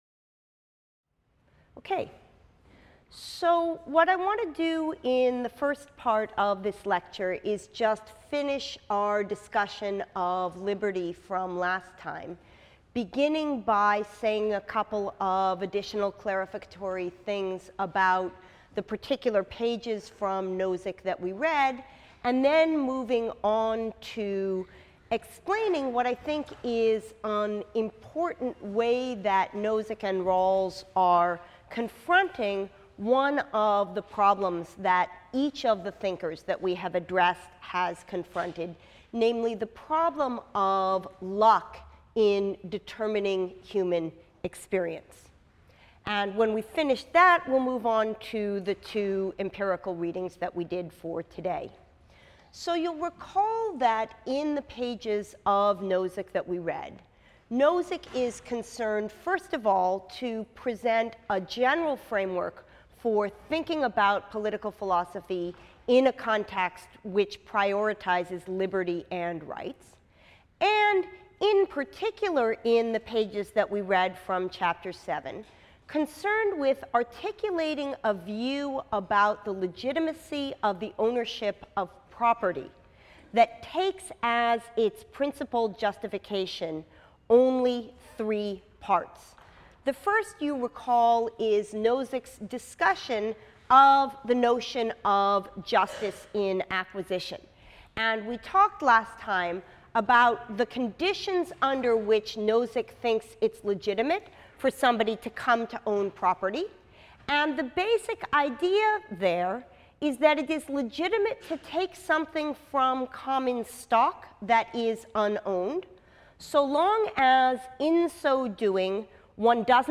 PHIL 181 - Lecture 23 - Social Structures | Open Yale Courses